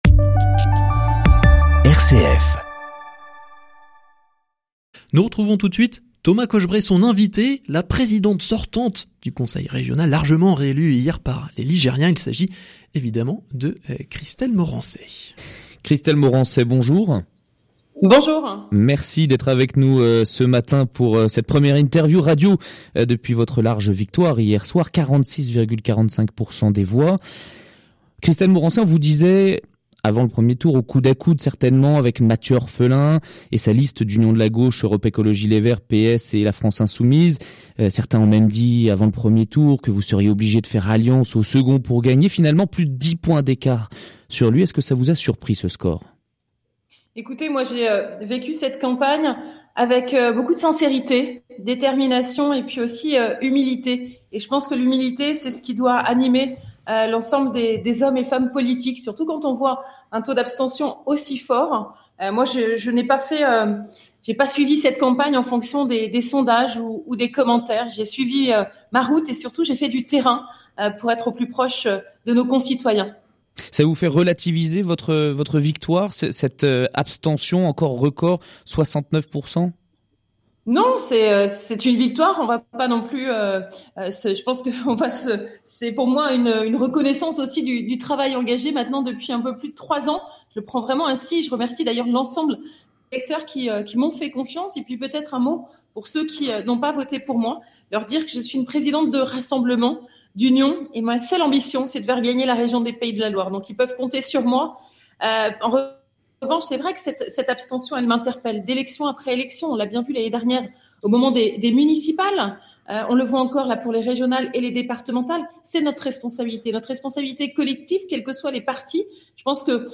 Élections régionales / Entretiens avec les têtes de liste : Christelle Morançais